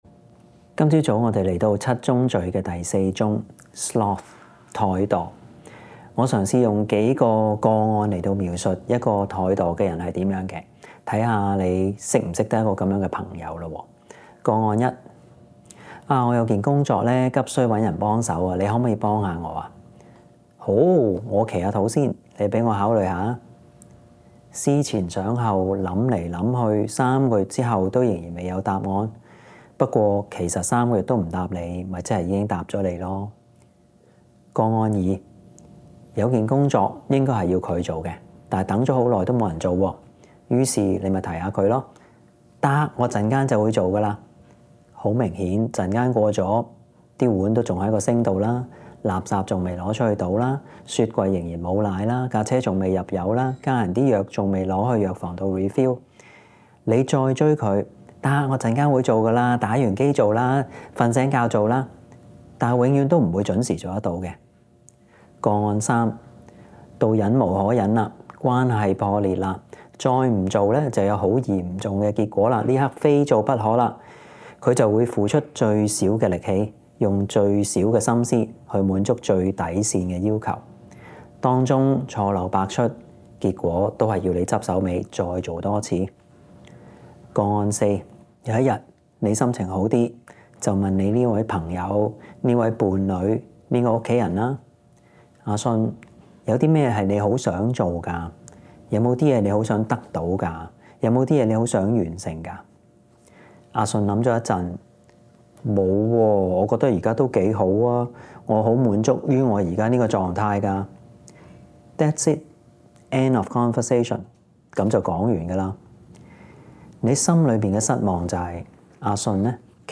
* 本週 YouTube 重温內容祇包括講道錄音及投影片，敬請留意 *